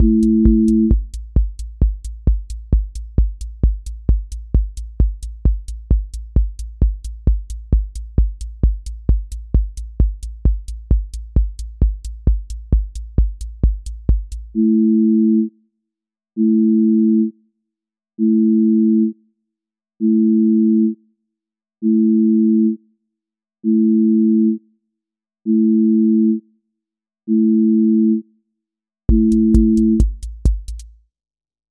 • voice_kick_808
• voice_hat_rimshot
• voice_sub_pulse
• fx_space_haze_light
• tone_brittle_edge
Trance ascent with breakdown and drop